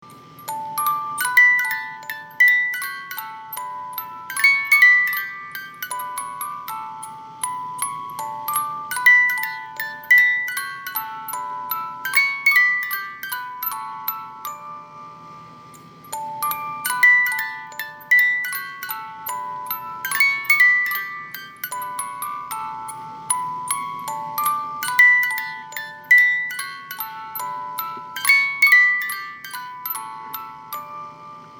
因為機械設計的限制，十八音梳機芯，只有十五秒左右的旋律，上發條後，這十五秒左右的旋律會重覆的撥放，直到發條鬆了為止！
機芯轉動時皆會有運轉聲、金屬磨擦聲(電動機芯也會有馬達運轉聲)，請知悉。